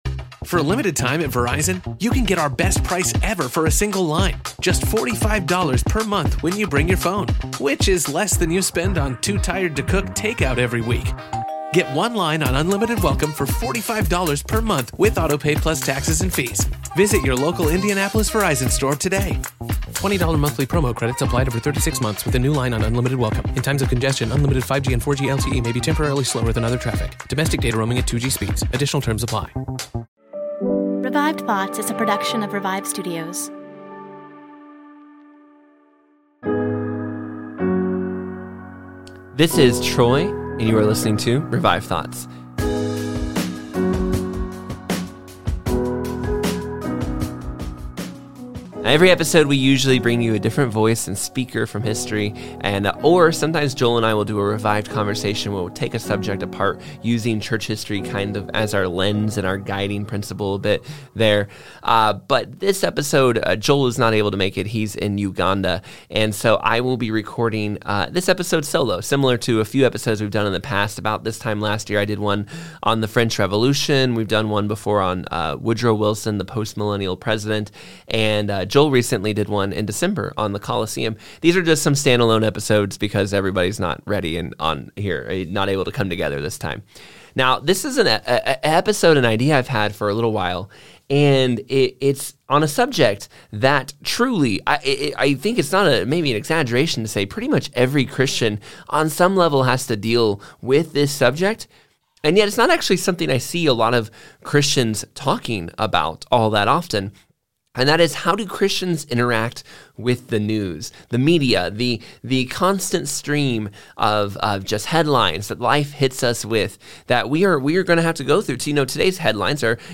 We are bringing history's greatest sermons back to life!
Each episode features a 5-10 minute backstory on who the preacher is to better understand the sermon's context. And each sermon has had its language updated for easy listening for the 21st century believer.